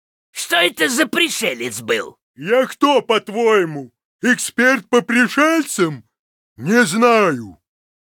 Вместо этого предлагаем ознакомиться с новыми звуковыми файлами из игрового клиента Heroes of the Storm, добавленными вместе с новым героем — Потерявшимися Викингами.